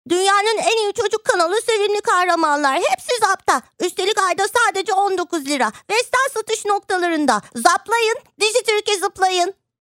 Turkish female voice over talent, turkish e-learning female voice, turkish female narrator
Sprechprobe: Sonstiges (Muttersprache):